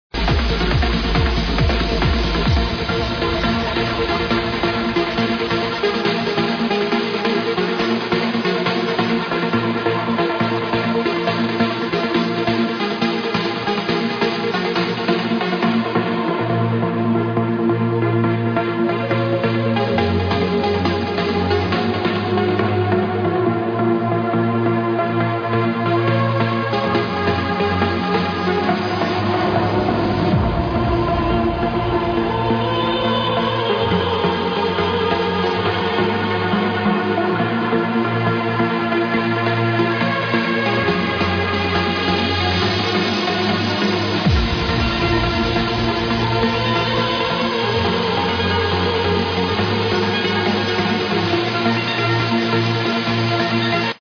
the blatant opera sample